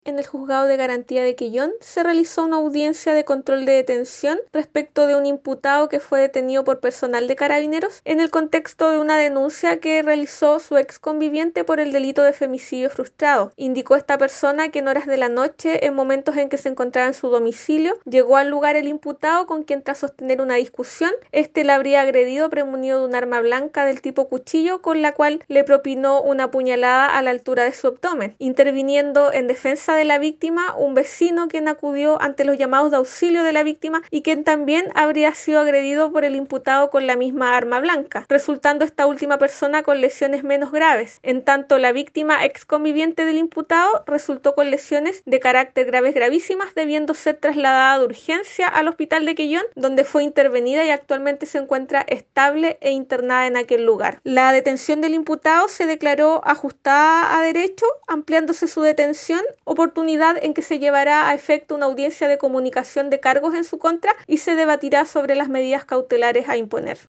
Más antecedentes entregó la fiscal subrogante de Quellón Paulina Otero: